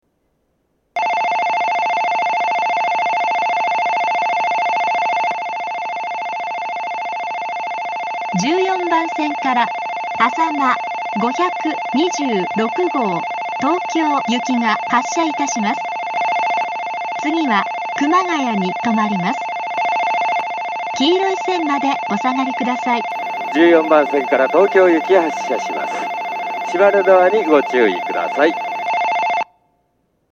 在来線では全ホーム同じ発車メロディーが流れますが、新幹線ホームは全ホーム同じ音色のベルが流れます。
１４番線発車ベル 主に長野新幹線が使用するホームです。
あさま５２６号東京行の放送です。